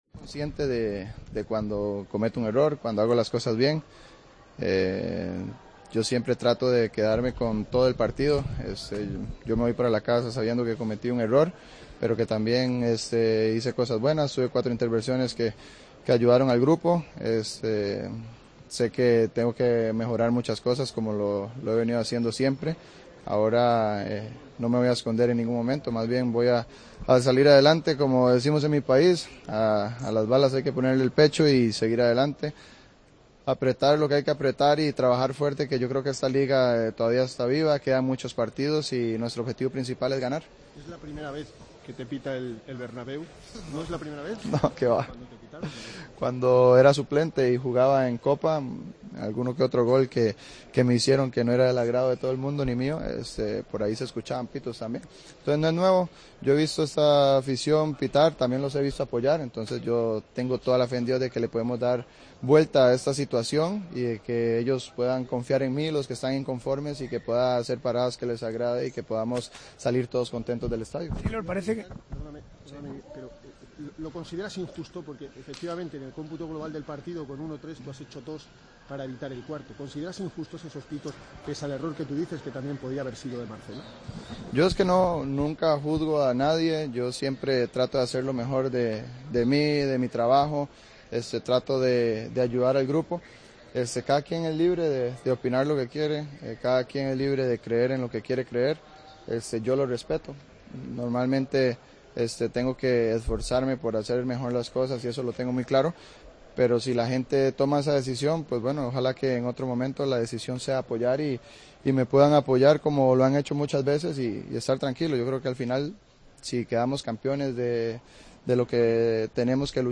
Respeto la decisión de todo el mundo", comentó el portero del Real Madrid, en zona mixta.